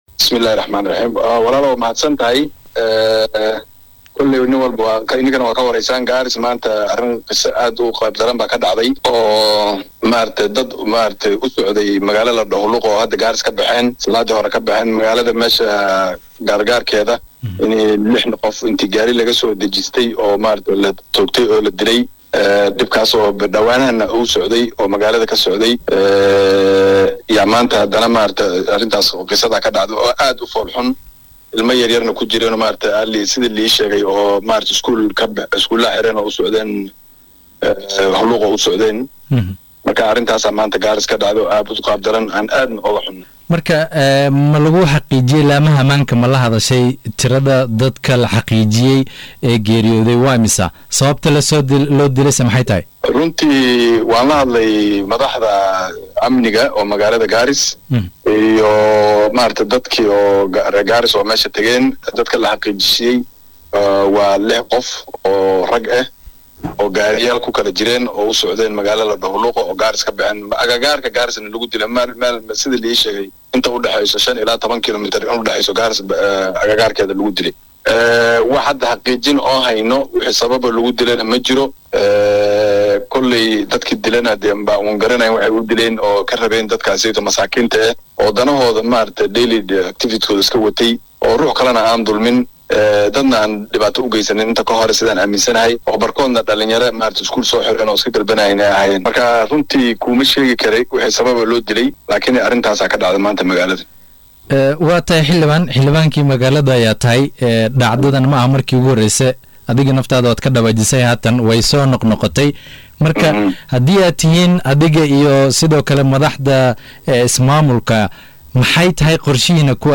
Mudanahan oo wareysi siiyay warbaahinta Star ayaa sheegay inuu aad uga xun yahay dilka loo gaystay dhallinyaro aan waxba galabsan.